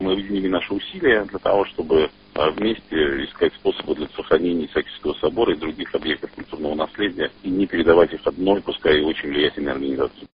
Говорит депутат Законодательного собрания Петербурга Максим Резник